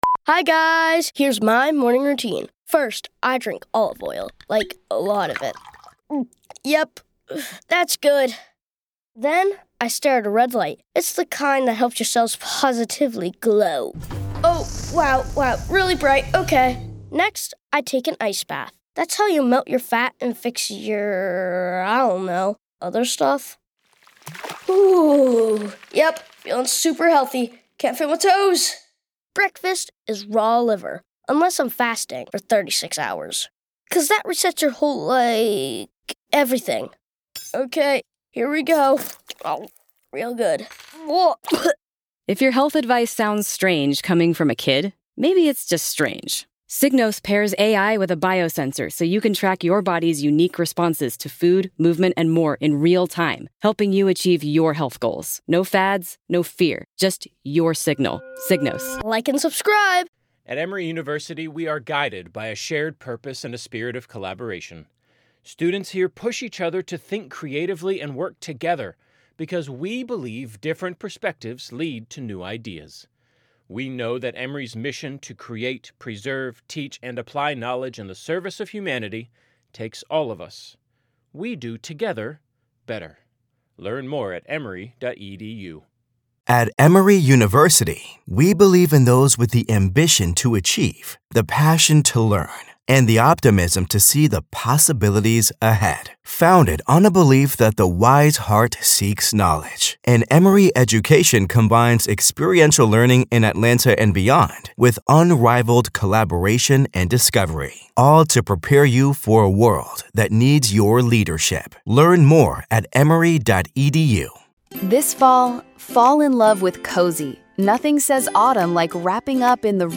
In this segment, we're joined by defense attorney and former prosecutor